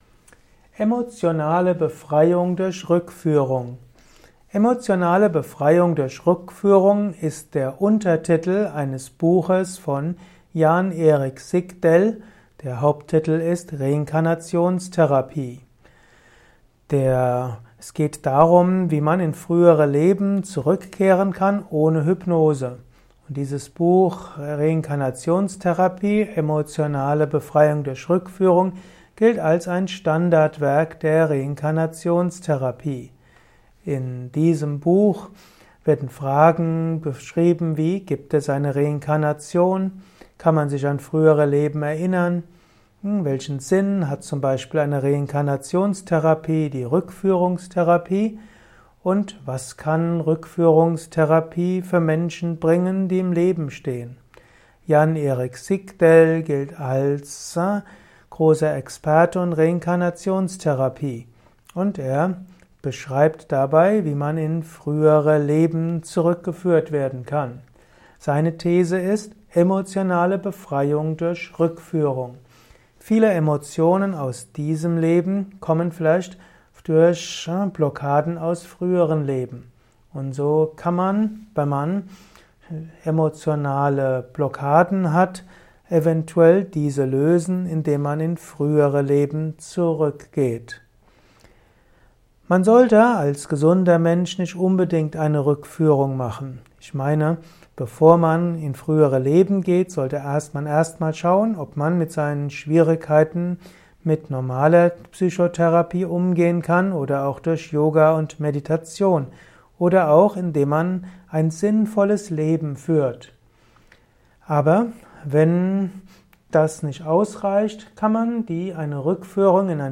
Vortragsaudio rund um das Thema Emotionale Befreiung durch Rückführung. Erfahre einiges zum Thema Emotionale Befreiung durch Rückführung in diesem kurzen Improvisations-Vortrag.